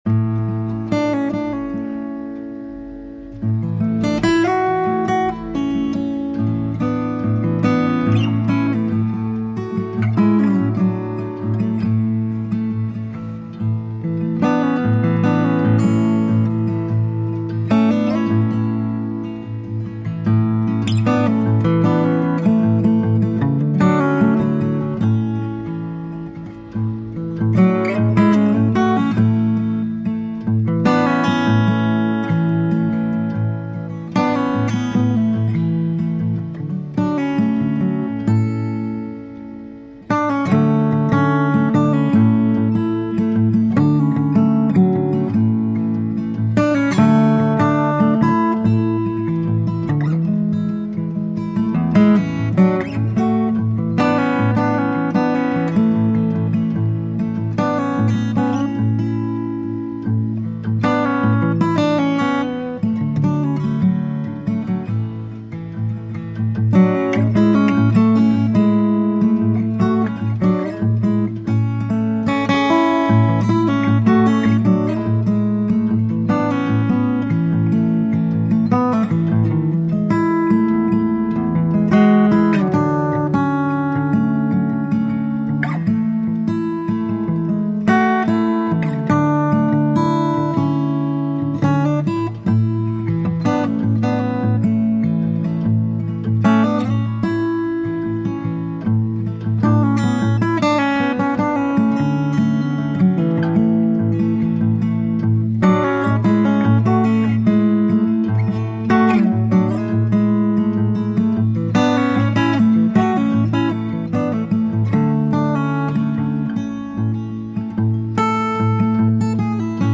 Bluesy and jazzy guitar.
Tagged as: Jazz, Blues, Instrumental Jazz, Acoustic Guitar